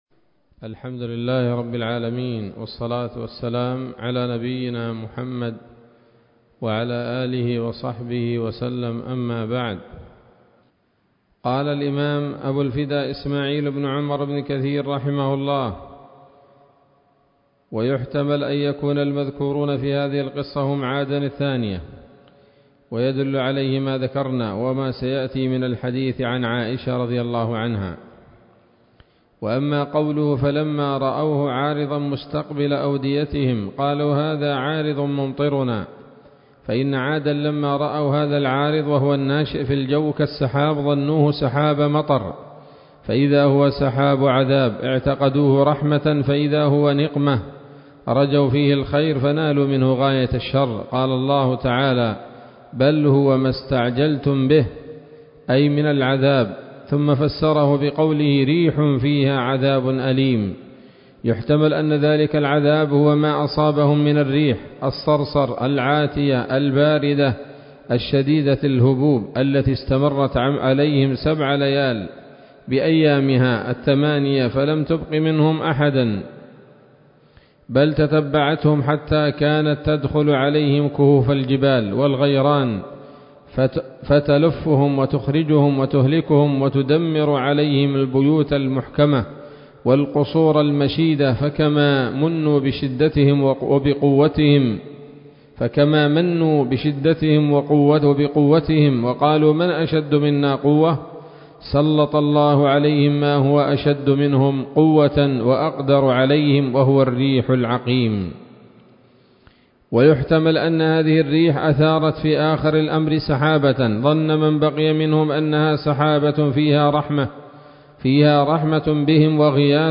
الدرس الثالث والثلاثون من قصص الأنبياء لابن كثير رحمه الله تعالى